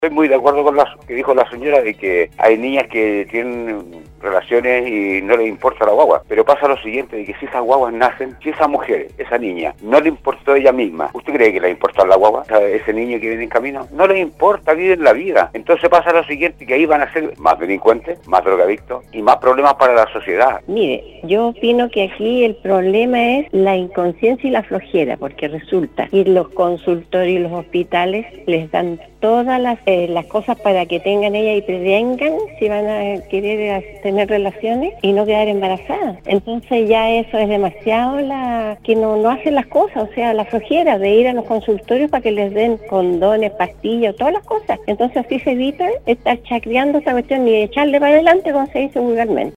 En relación a algunas manifestaciones que se han registrado en algunos países del mundo sobre una nueva campaña en favor del Aborto, algunos auditores expresaron sus inquietudes y opiniones con respecto a este tema que continua siendo controversial y generando polémica en la sociedad.